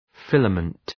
Προφορά
{‘fıləmənt}
filament.mp3